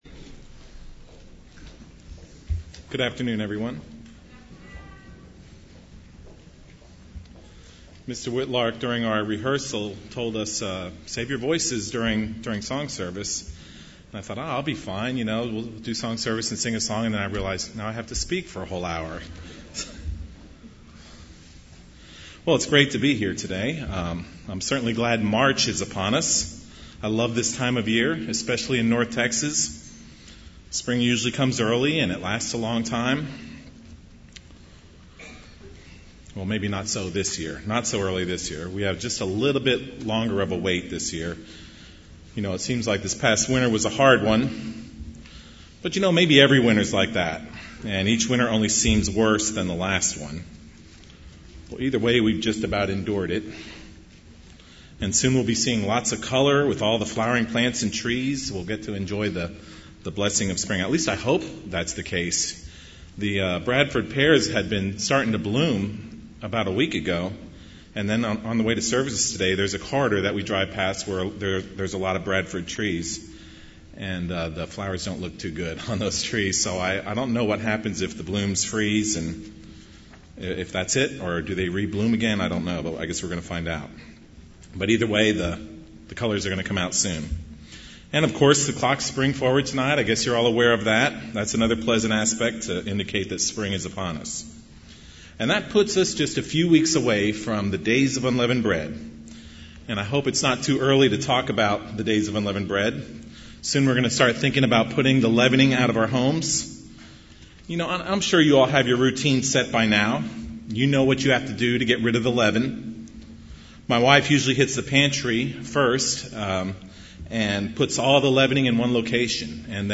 Given in Dallas, TX